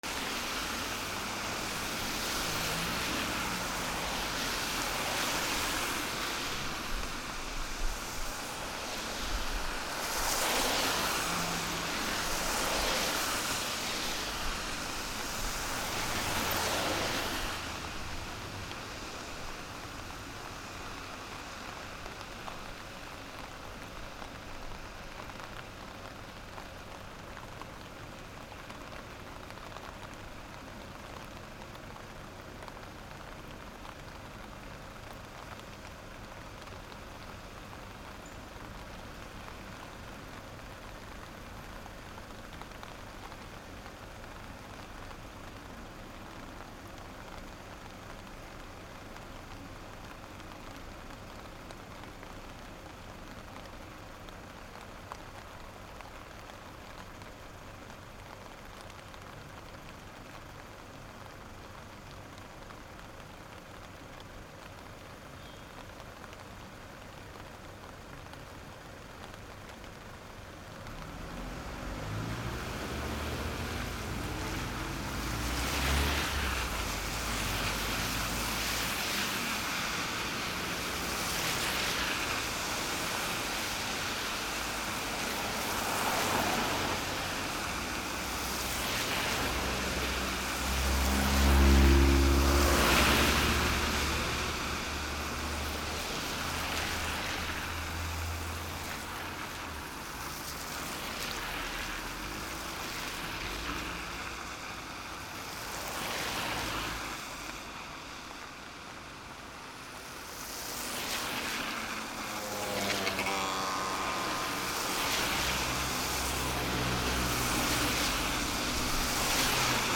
/ A｜環境音(天候) / A-30 ｜雨 道路
雨 道路
サー 04